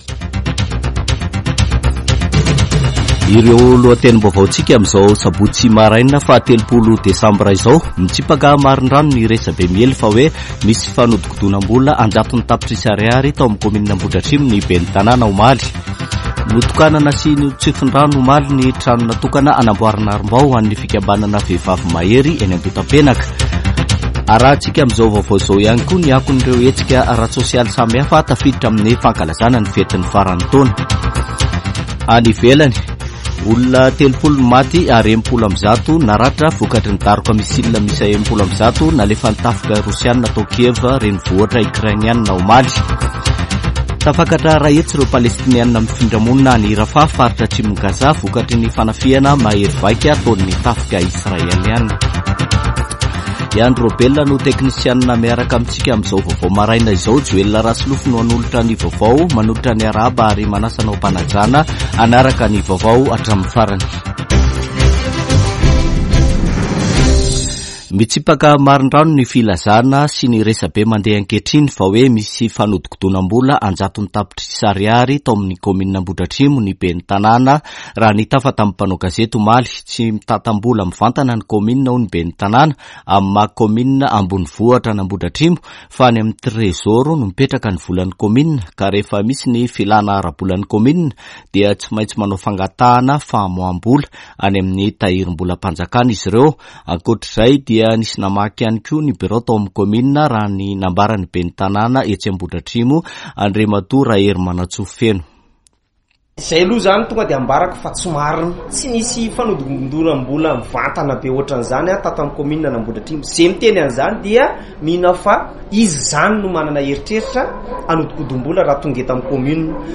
[Vaovao maraina] Sabotsy 30 desambra 2023